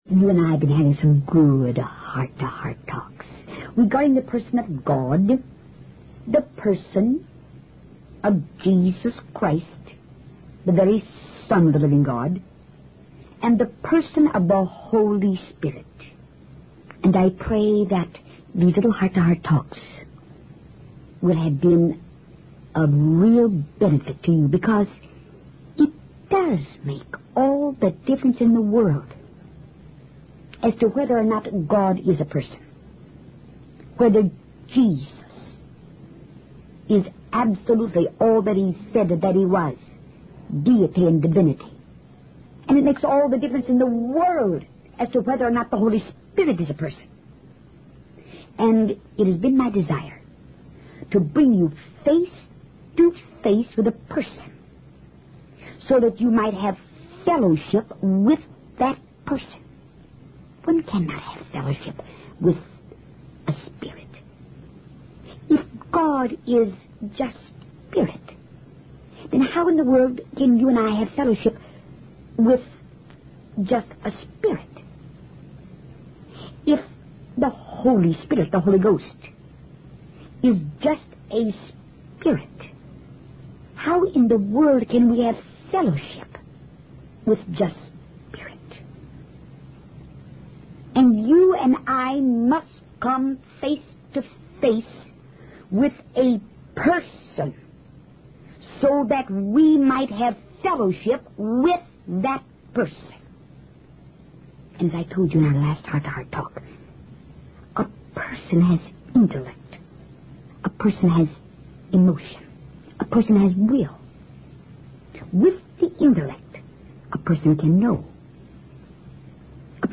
In this sermon, the preacher emphasizes the unchanging truth of God's word.